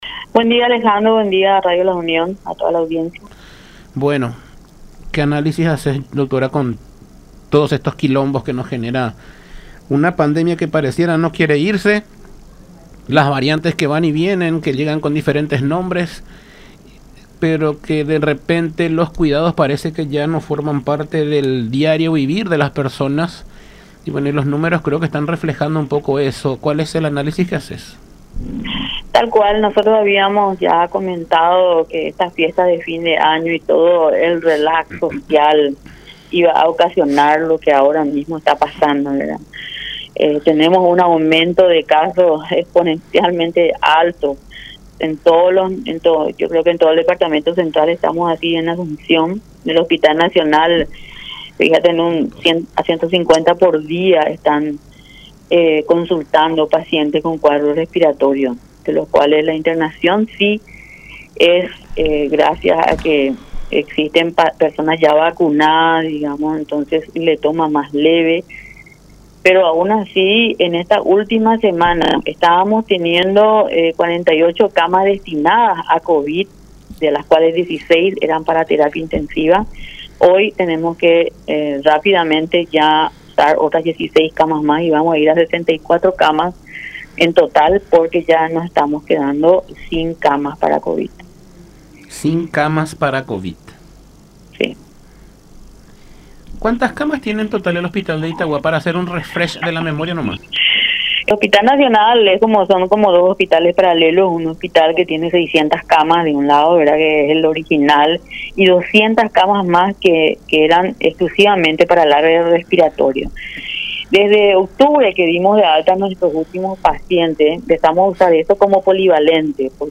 en conversación con Todas Las Voces por La Unión